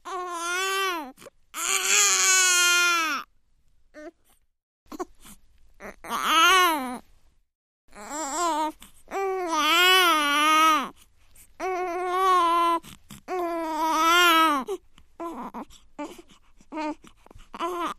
Newborn Baby Wail Short x3